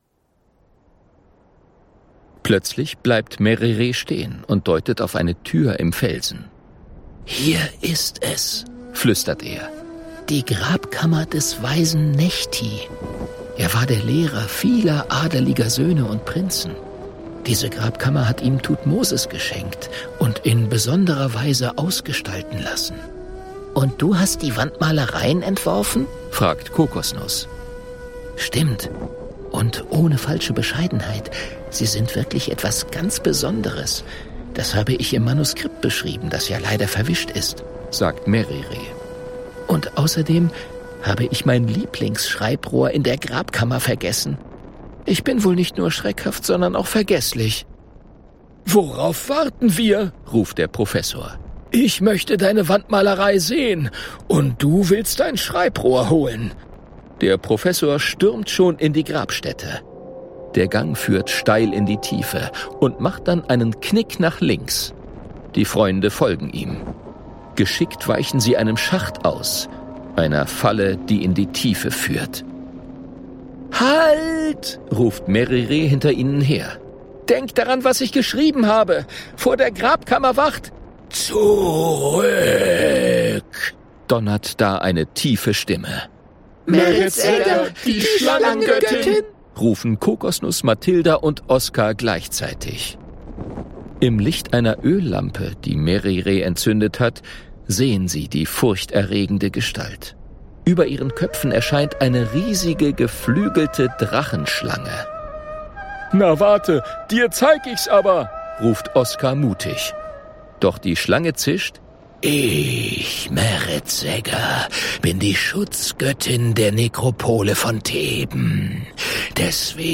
Hörbuch: Alles klar!